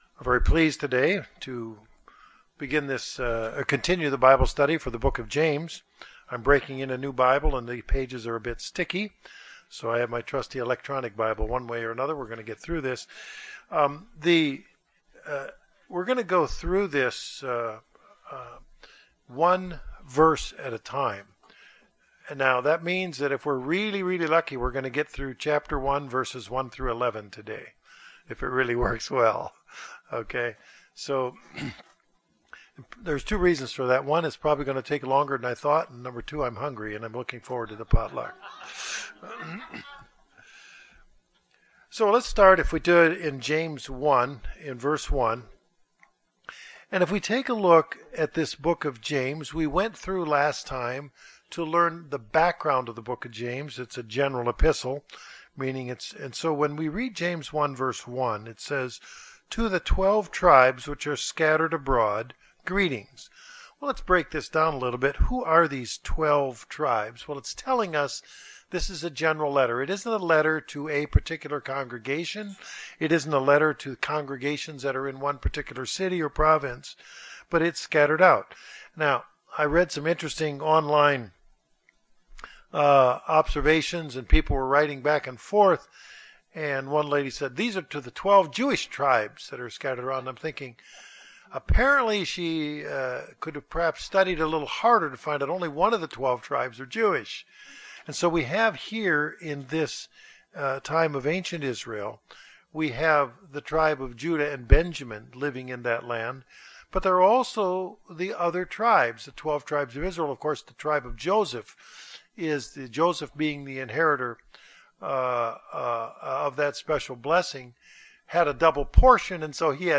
This begins an in-depth Bible Study of the Book of James.
Given in Northwest Arkansas